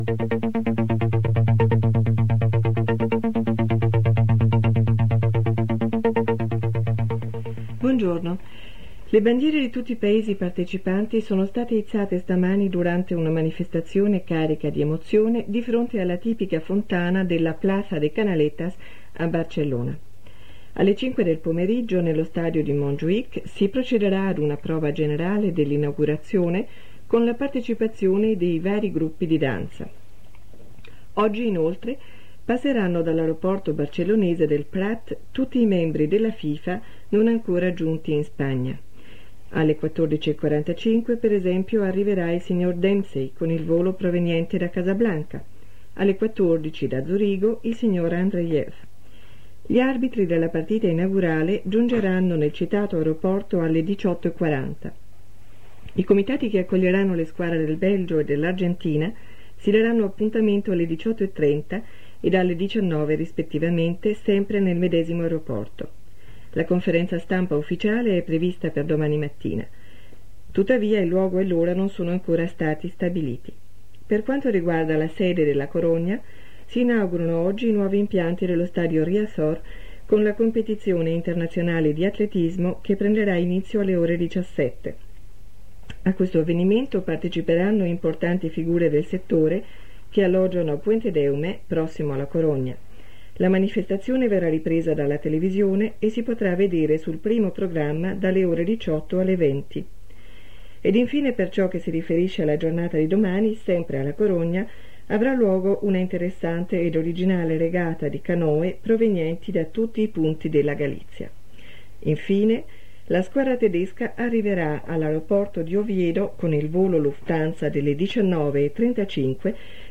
Notícies en italià i portuguès. Indicatiu i careta informatiu multilingüe. Notícies en castellà, anglès: Guerra de les Malvines, Portugal, Reunió del Partido Comunista de España, vaga controladors aeroports,etc.
Informatiu